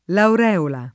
[ laur $ ola ]